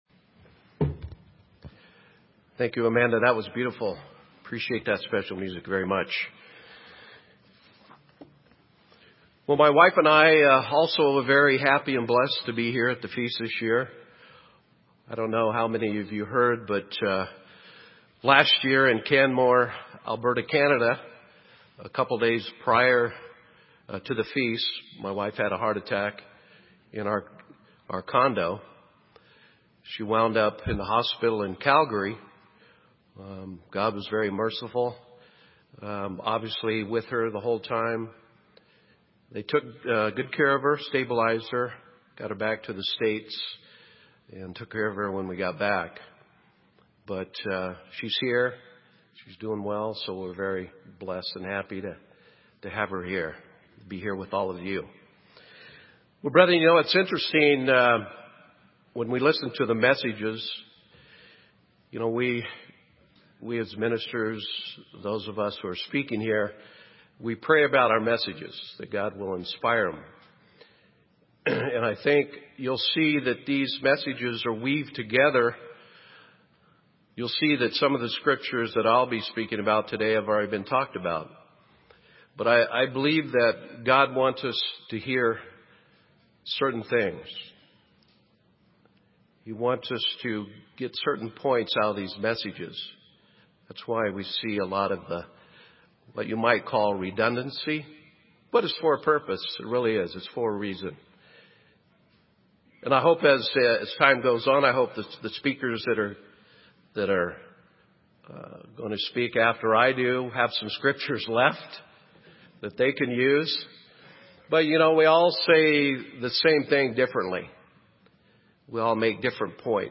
This sermon was given at the Bend, Oregon 2014 Feast site.